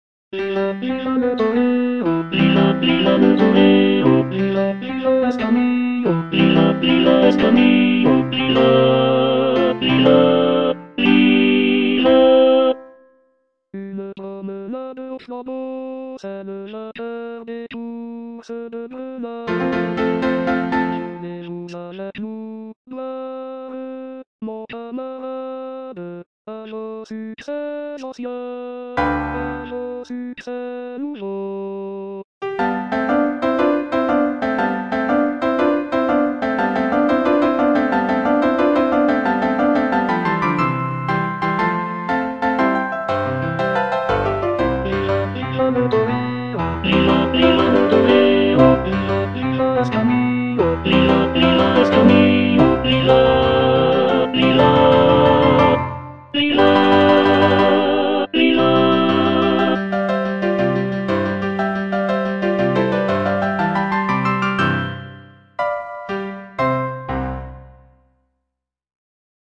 (tenor II) (Emphasised voice and other voices)